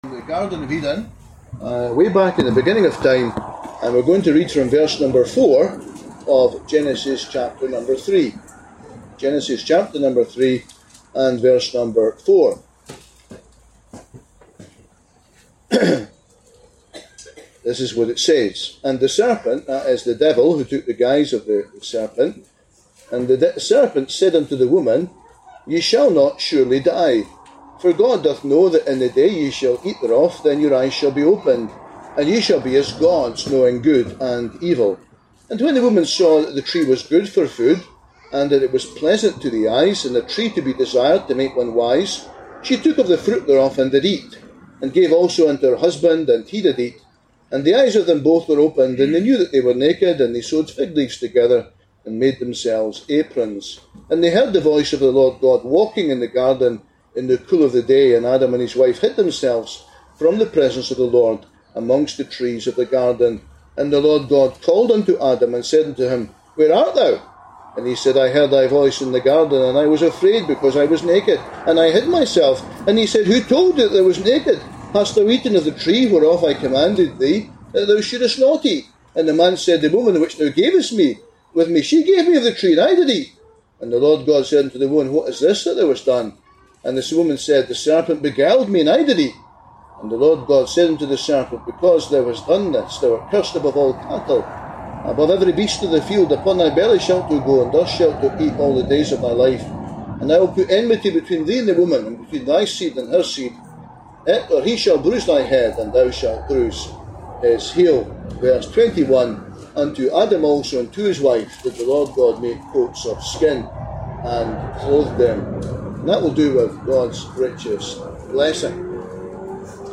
Gospel Tent ’25: What’s wrong with the world? (25 mins) – Hebron Gospel Hall